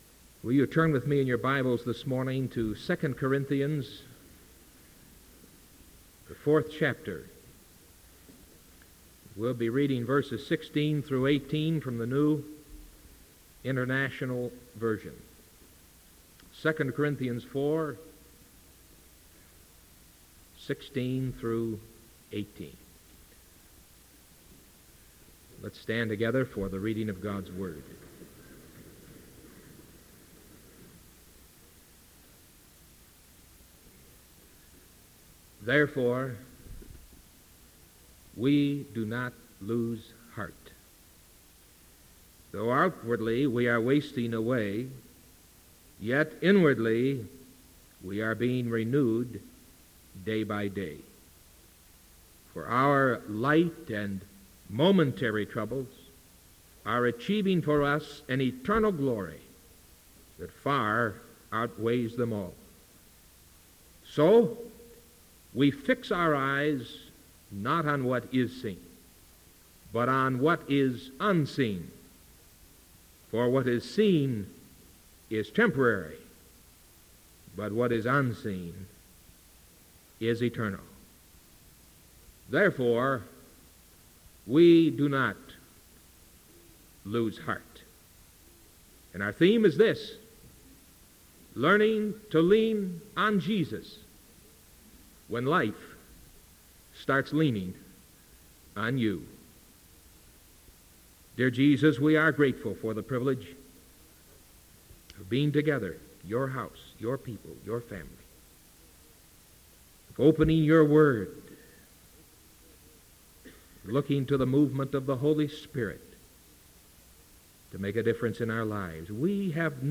Sermon August 13th 1978 AM